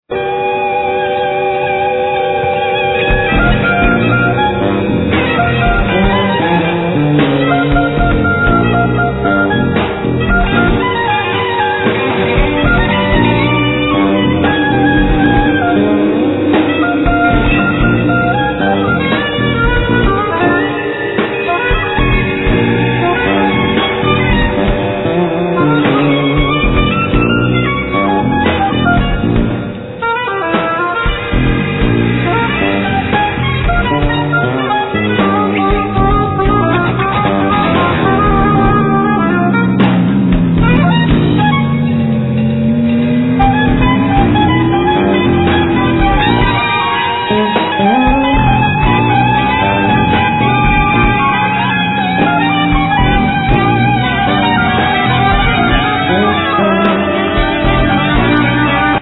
Drums
Bass
Alt sax
Percussions
Guitar, Effects